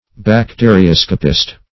Bacterioscopist \Bac*te`ri*os"co*pist\, n. (Biol.)